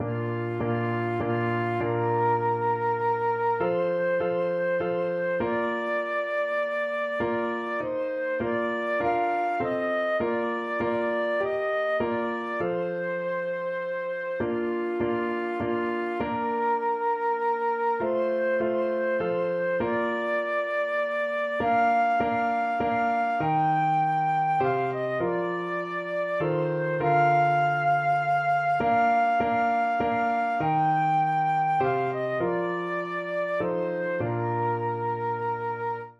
Christian Christian Flute Sheet Music Come, Holy Ghost
Flute
3/4 (View more 3/4 Music)
F5-G6
Bb major (Sounding Pitch) (View more Bb major Music for Flute )
Classical (View more Classical Flute Music)